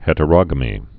(hĕtə-rŏgə-mē)